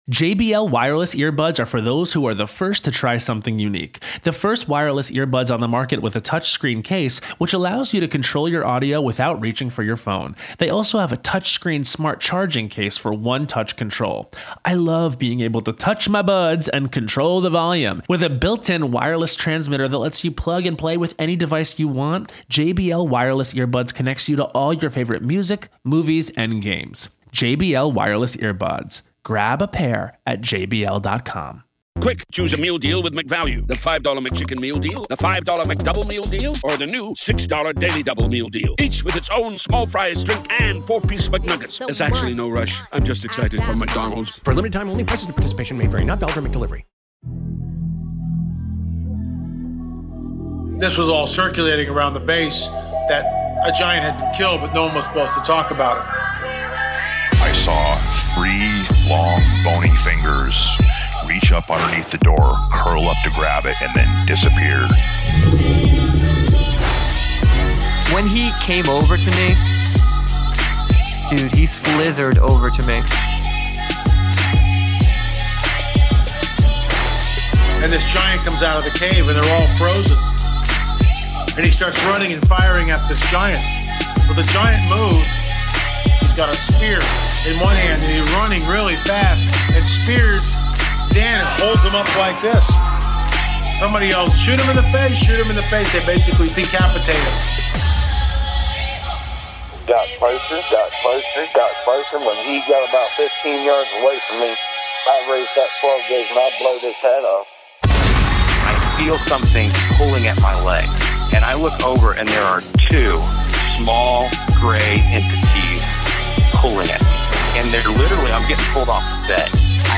tell-all conversation about China’s organ harvesting industry